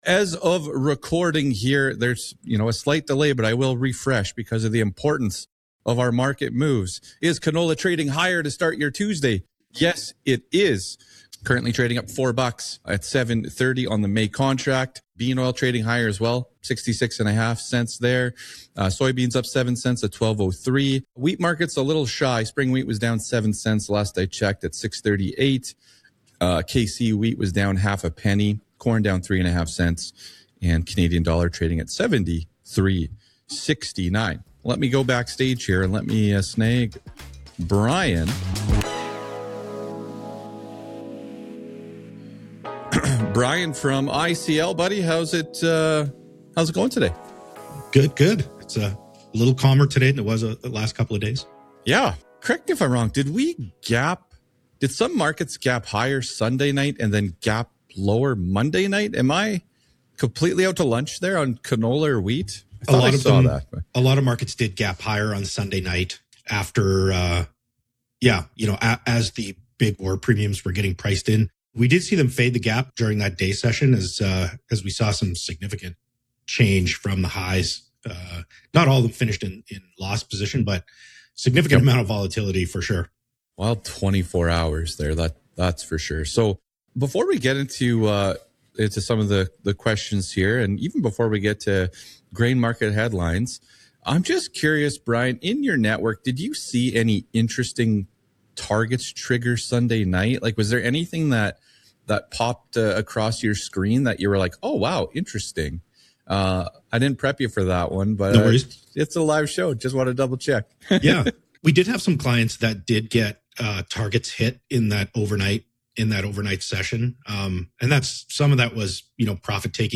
Wheat Futures Are Rallying But Farmers Aren’t Getting Paid More | CUPPA COFFEE (LIVE)